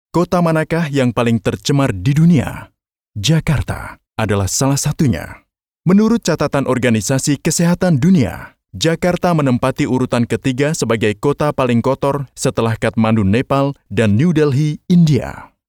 Sprechprobe: Industrie (Muttersprache):
Lite-Documentary_01.mp3